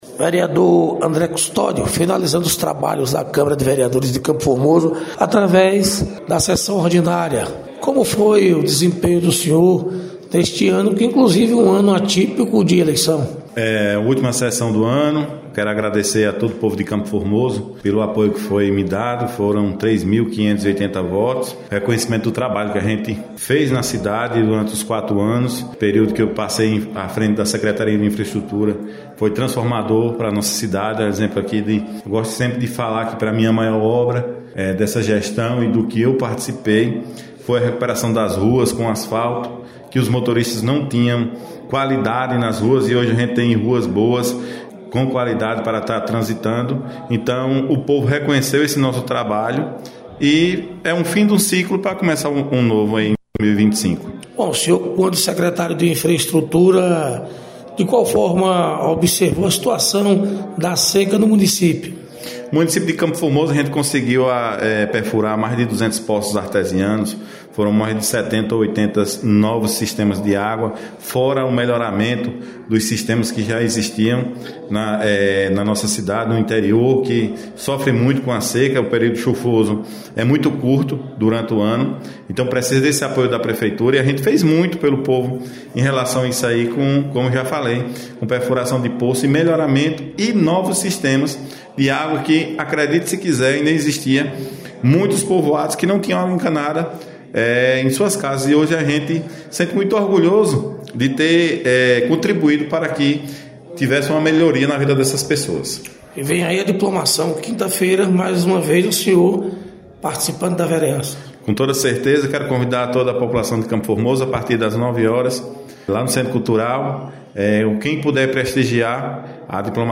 Reportagem com os vereadores de CFormoso